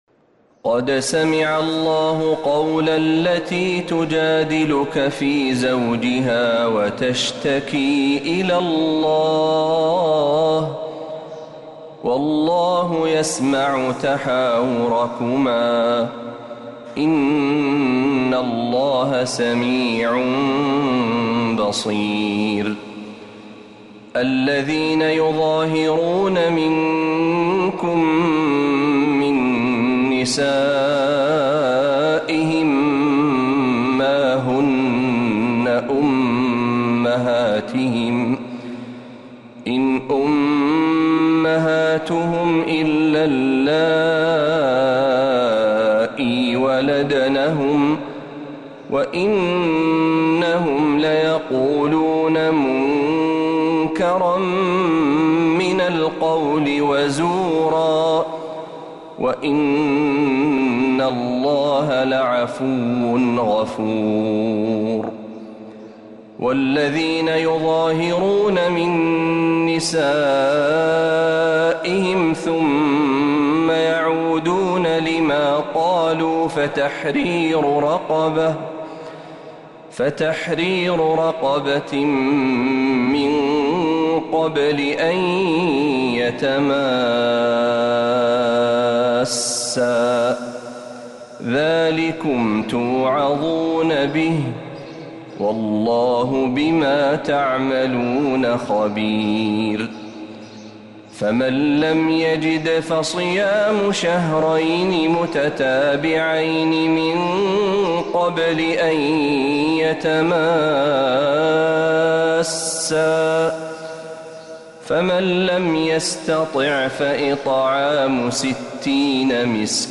سورة المجادلة كاملة من الحرم النبوي